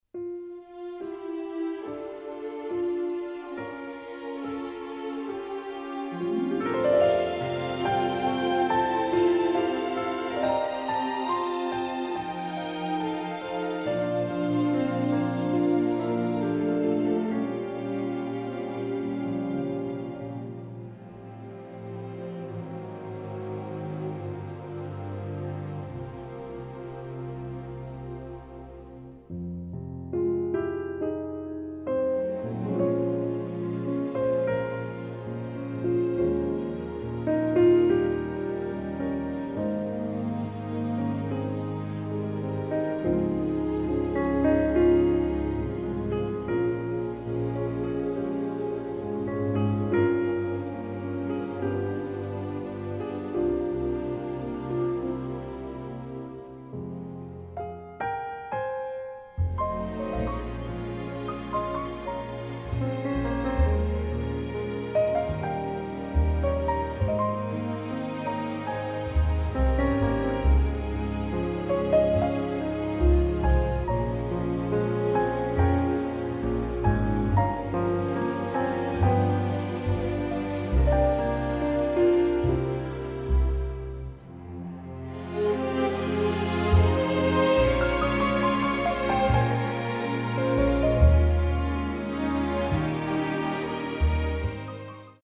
This orchestral album
A nice easy listening version of music